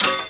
SND_DROP_TOOL.mp3